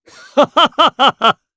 Clause_ice-Vox_Happy3_jp.wav